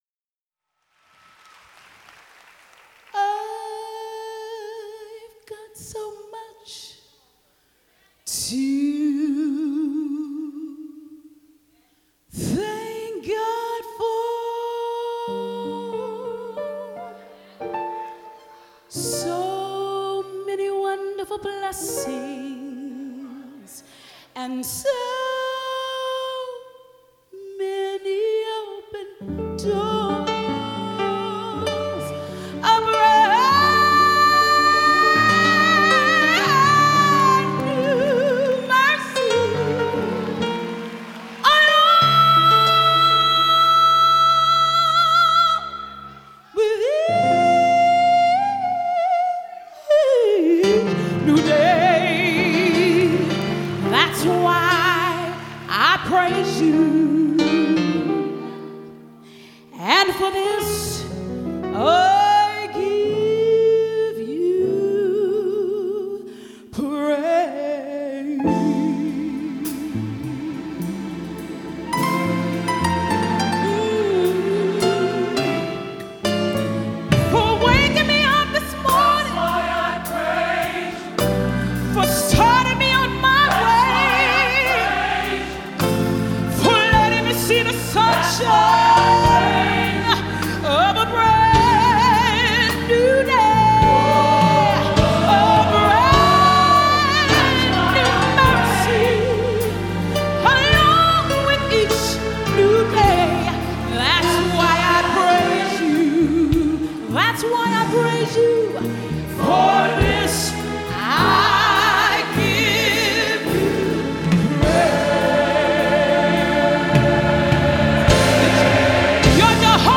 Orchestra/Choir